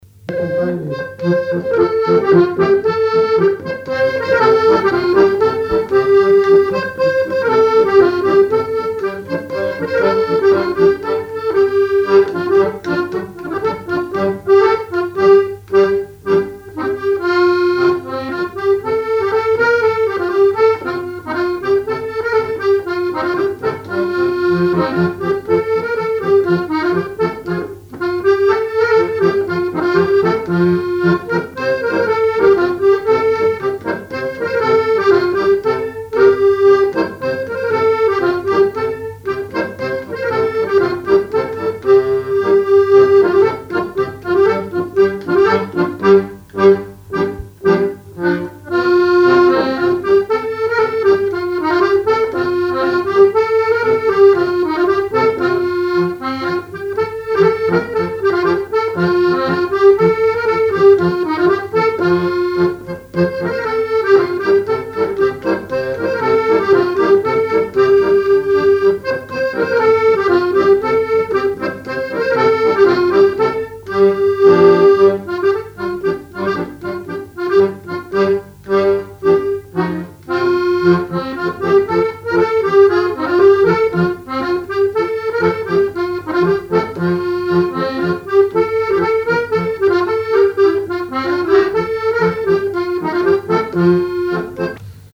danse : ronde : grand'danse
Genre énumérative
Répertoire sur accordéon chromatique
Pièce musicale inédite